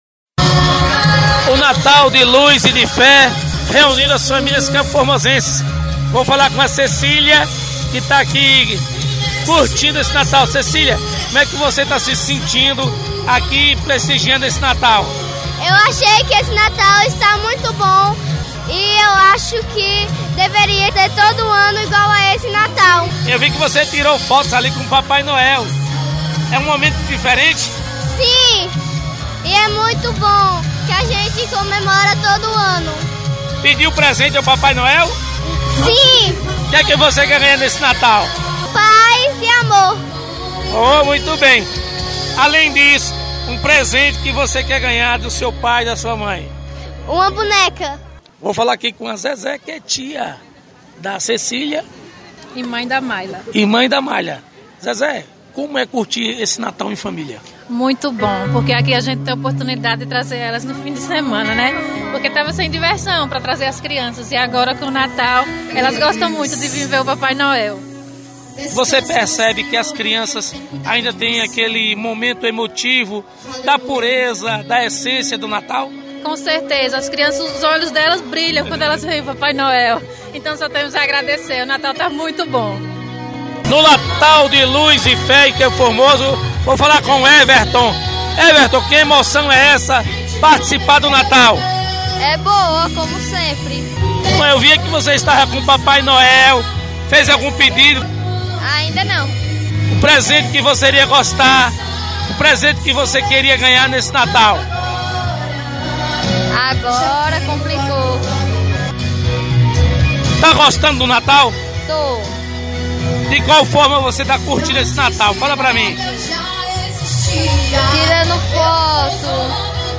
Entrevista: projeto Natal de Luz em CFormoso